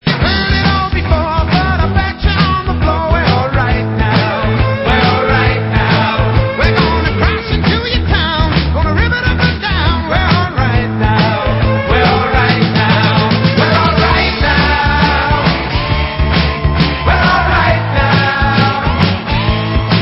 • styl: Glam